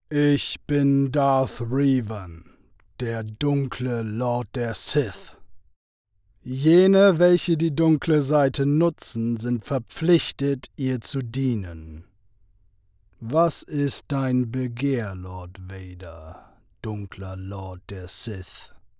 Beim Druiden habe ich mich mehr als die etwas weniger quirlige Variante von C3PO verstanden.